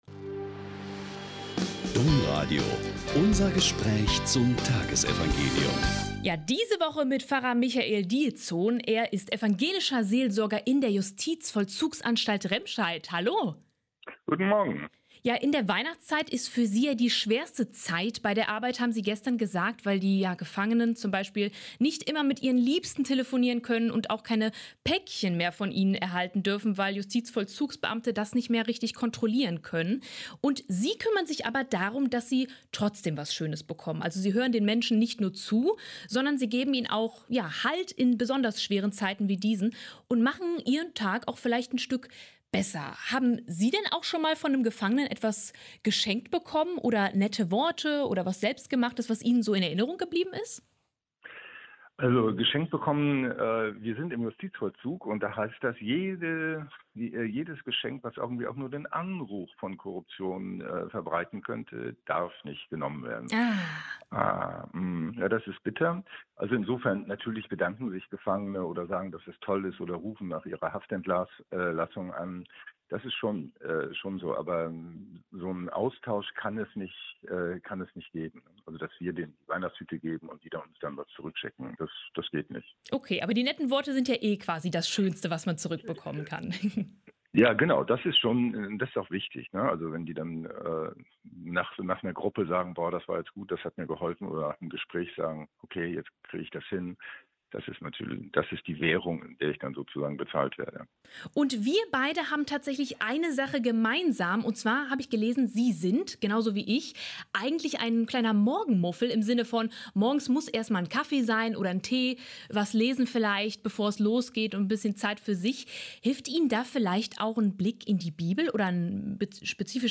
Lk 16,9-15 - Gespräch